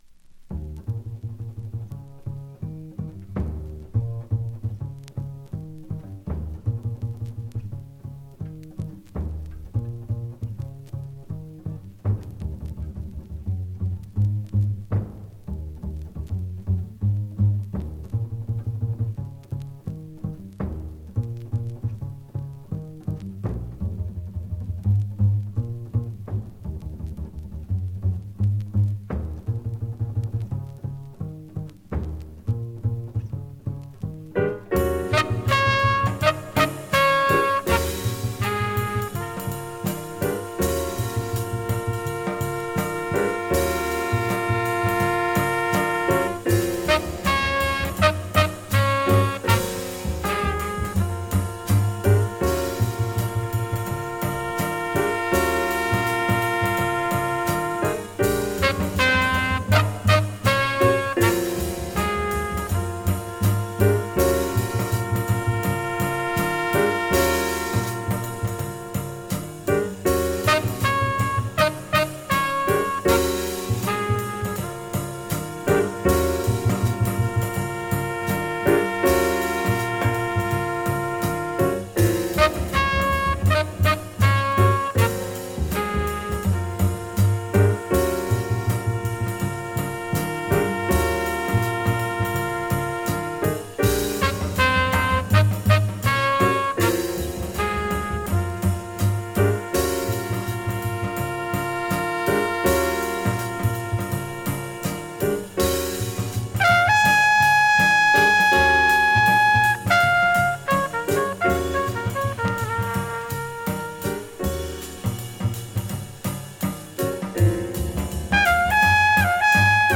ディープでエキゾチックな「